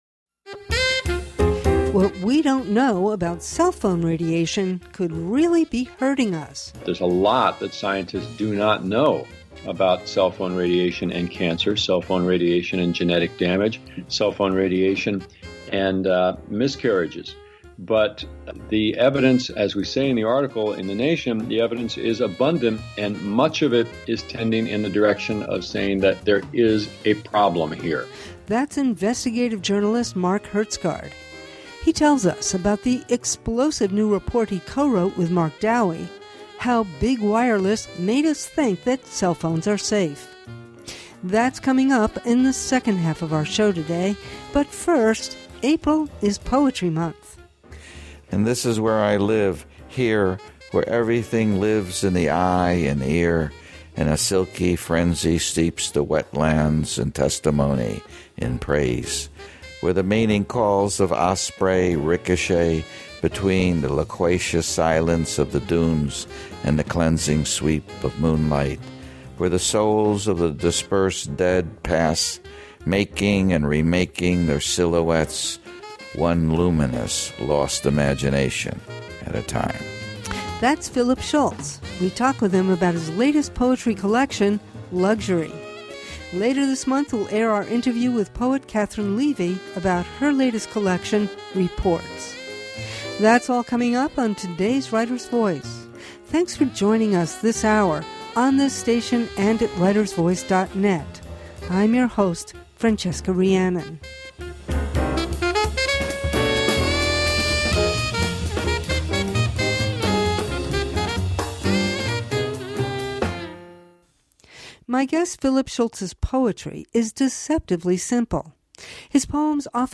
Writers Voice— in depth conversation with writers of all genres, on the air since 2004.